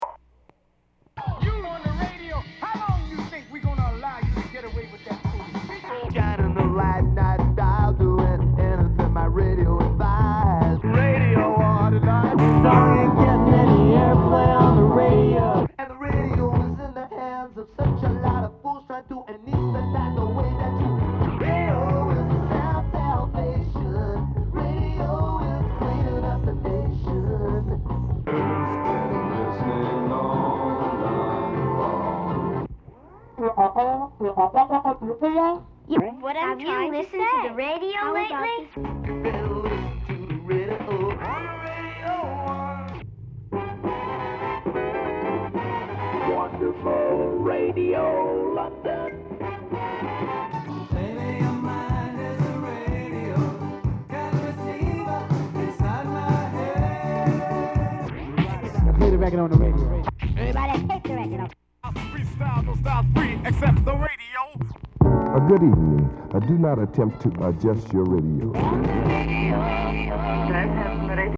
free103point9 original radio collage (Audio)
03 original radio collage.mp3